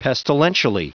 Prononciation du mot pestilentially en anglais (fichier audio)
Prononciation du mot : pestilentially